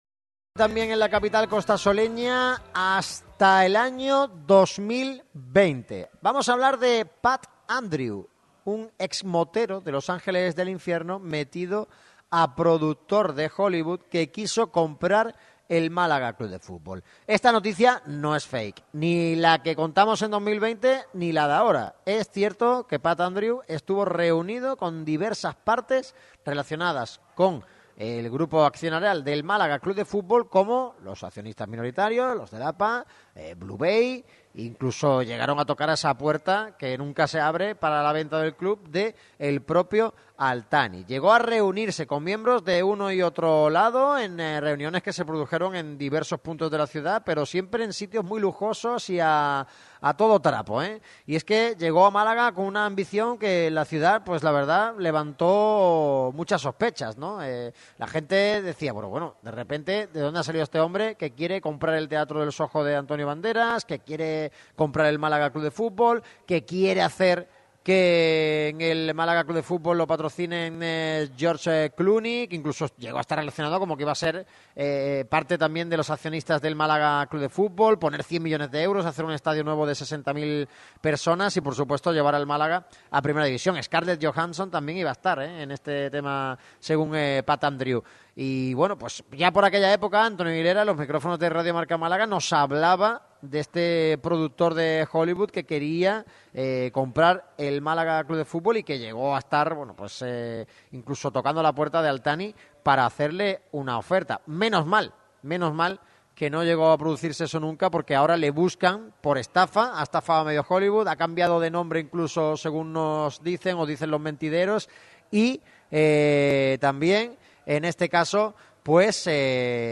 ha pasado por el micrófono rojo de Radio MARCA Málaga